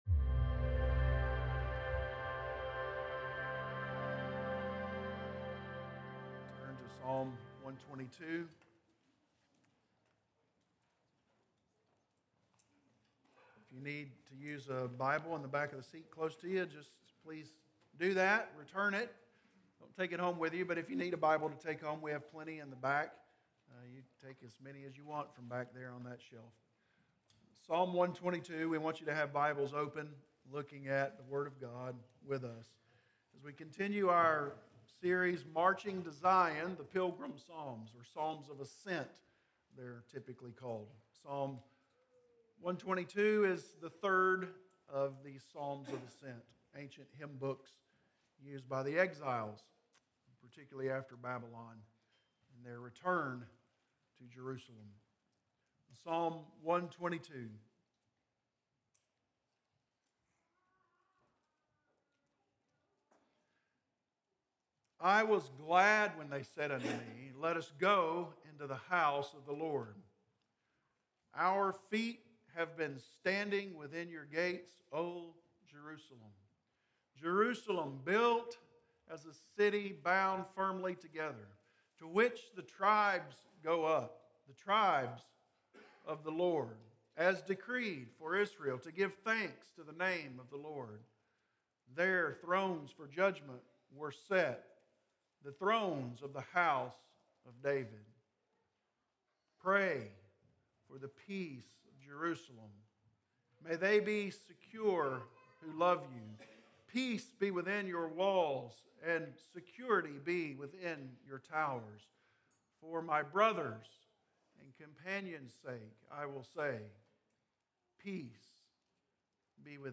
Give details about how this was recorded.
Sermons recorded during the Sunday morning service at Corydon Baptist Church in Corydon, Indiana